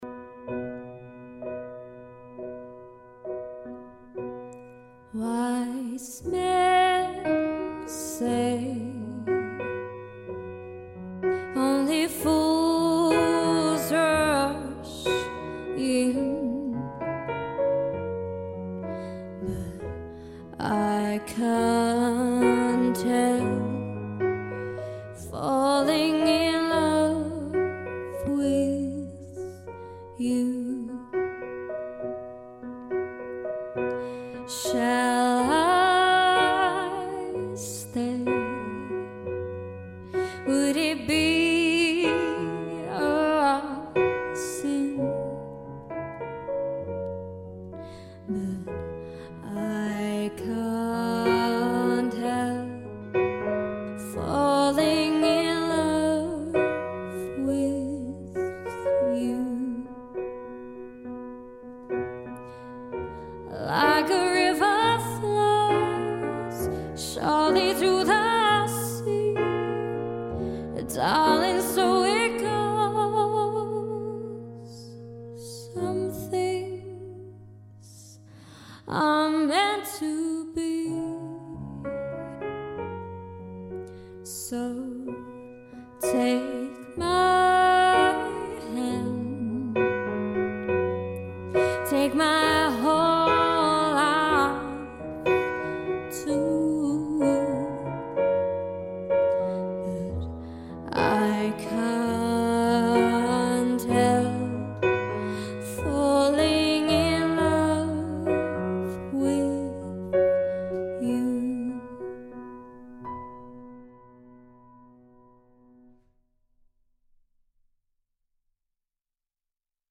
Female Vocals, Piano / Guitar